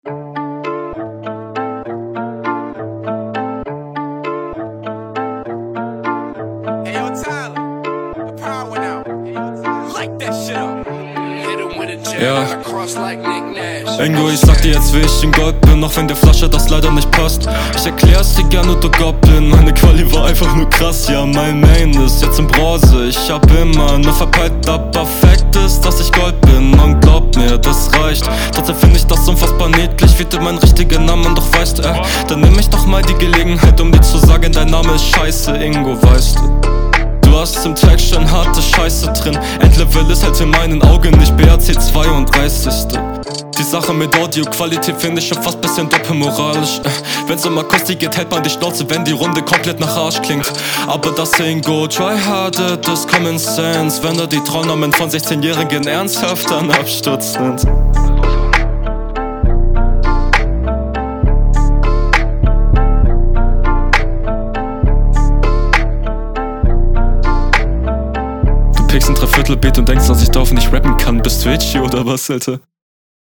flow fine, aber deutlich weniger anspruchsvoll und so betonungen wie in der brc line sind …